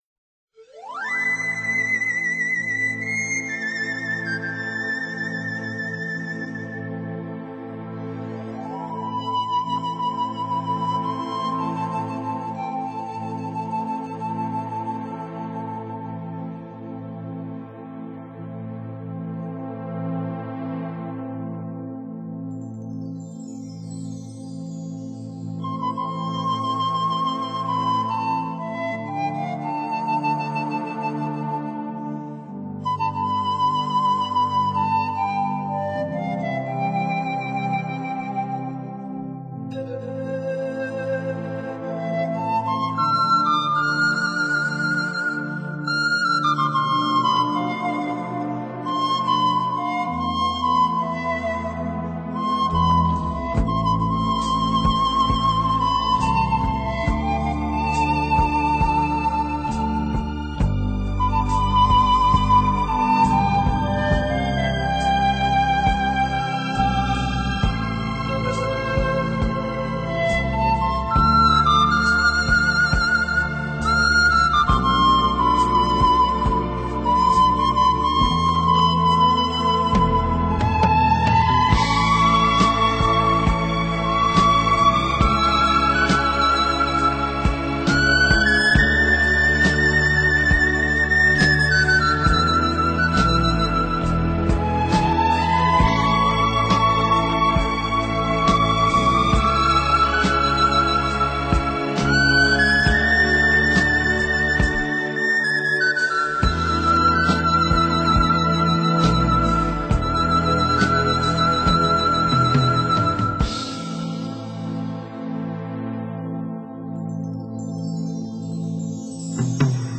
演唱歌手： 纯音乐